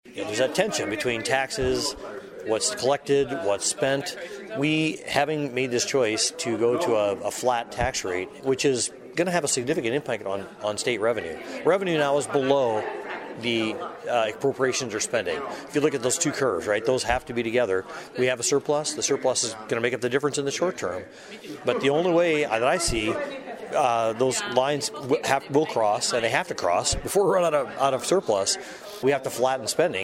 THE SIOUX CITY COUNCIL HELD THEIR ANNUAL MEETING AT CITY HALL WITH IOWA LAWMAKERSS FROM THE METRO AREA ON FRIDAY, AND SHARED A LIST OF CONCERNS AND GOALS FOR THE UPCOMING STATE LEGISLATIVE SESSION.
TARGETED JOBS OR TIF, ALONG WITH STATE PROPERTY TAX LIMITS AND THE EFFECT ON THE CITY BUDGET WERE TOPICS OF DISCUSSION FOR THE GROUP, AND STATE SENATOR KEVIN ALONS APPRECIATED THE CITY CONCERNS: